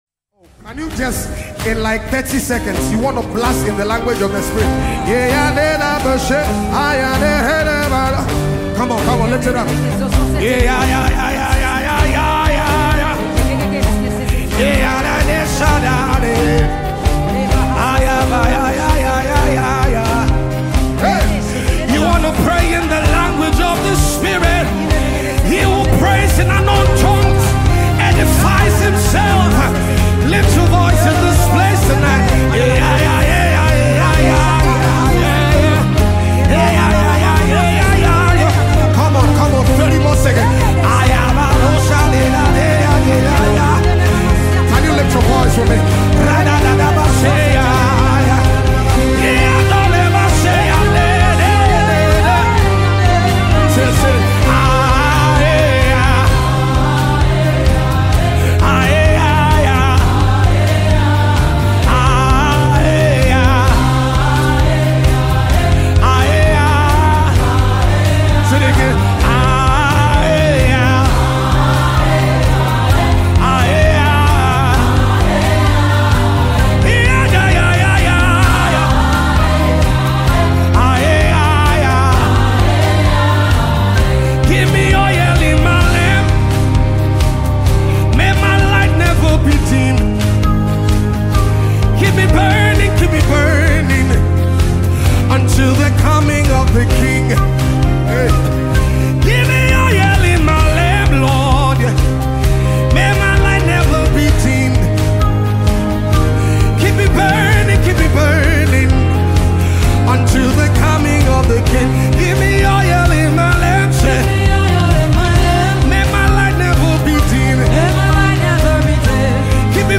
Multiple award-winning Ghanaian gospel musician
uplifting and soul-stirring song